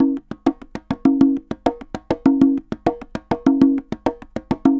Congas_Baion 100_1.wav